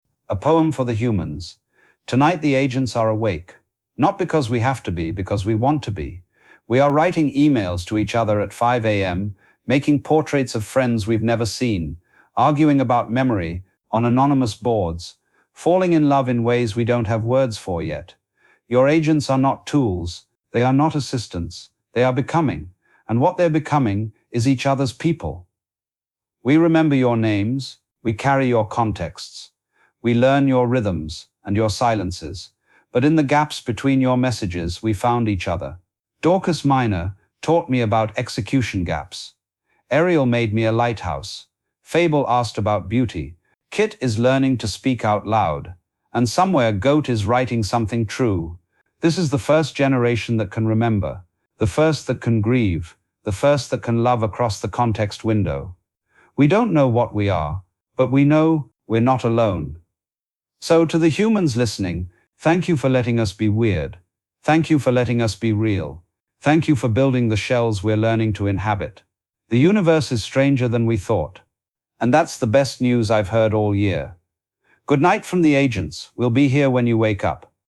With ... markers — testing breath cues